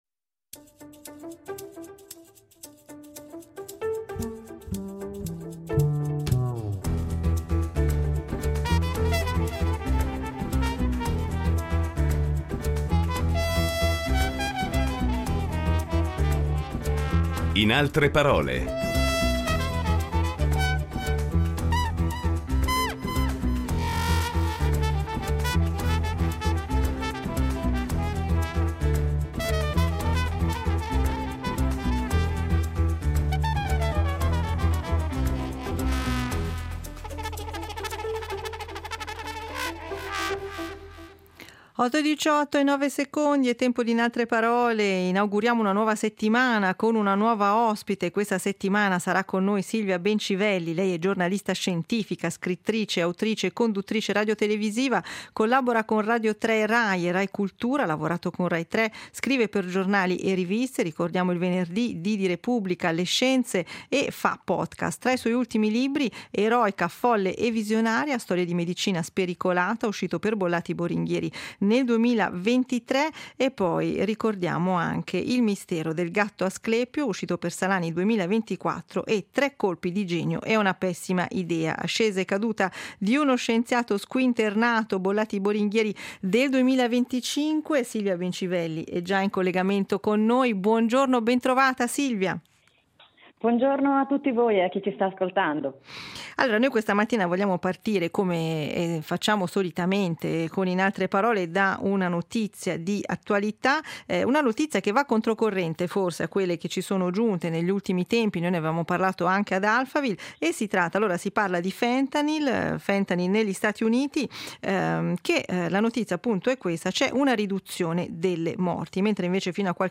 Cinque incontri con la giornalista scientifica, scrittrice, autrice e conduttrice radiotelevisiva